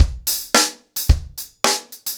DaveAndMe-110BPM.15.wav